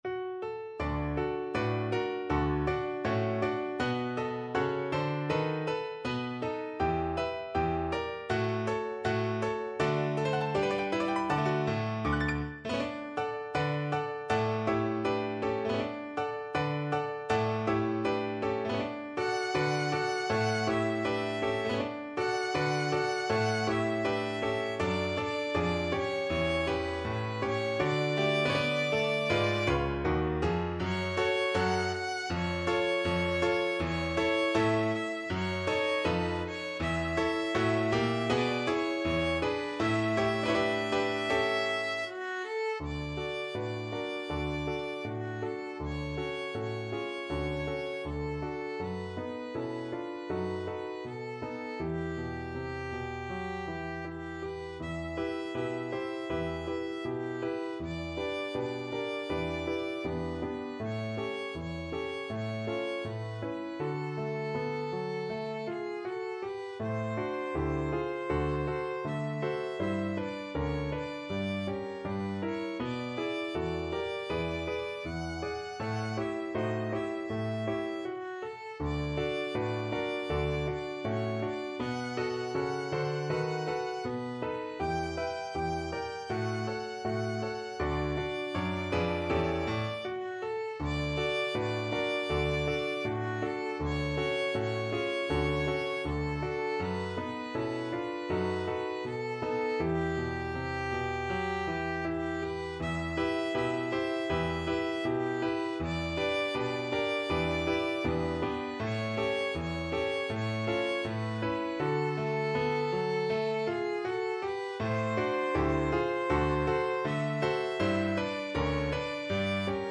2/2 (View more 2/2 Music)
F#5-G6
Moderato =80
Pop (View more Pop Violin Music)